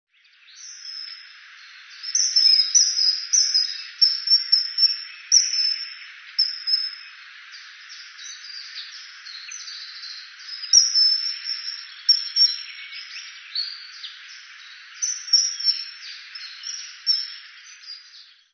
IJsvogel | Vogel- en Natuurwerkgroep Zundert
Zo klinkt deze schitterende vogel ...
ijsvogelmp3.mp3